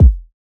SOUTHSIDE_kick_gritty.wav